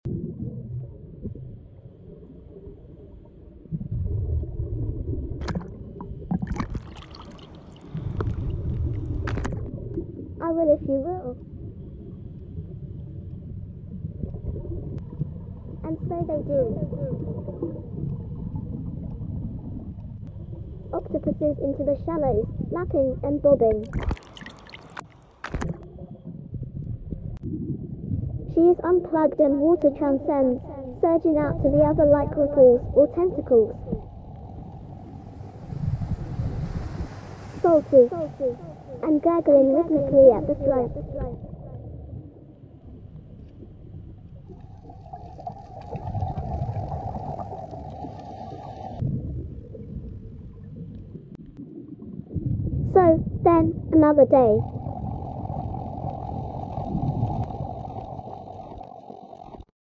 Sounds from home (elsewhere) are overlaid and geo-located along the canal.